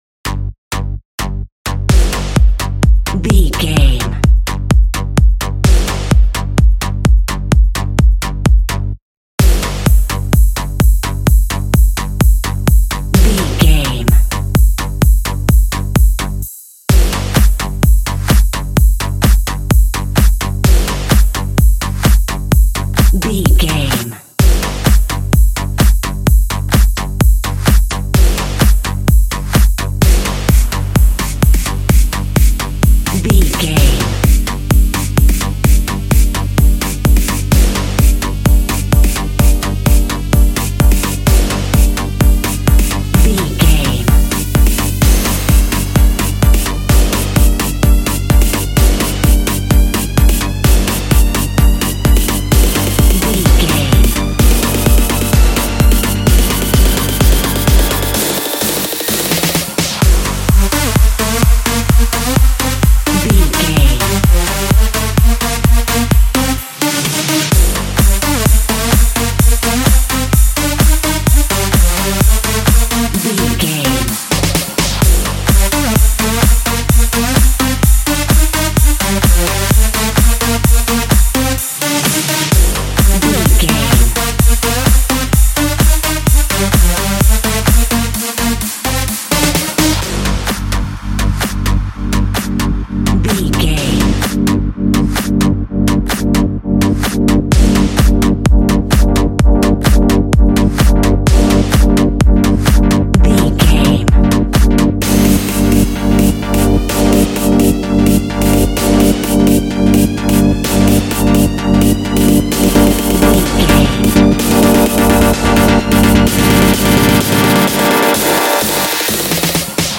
Trance for Trailers.
In-crescendo
Aeolian/Minor
energetic
hypnotic
frantic
drum machine
synthesiser
acid house
uptempo
synth leads
synth bass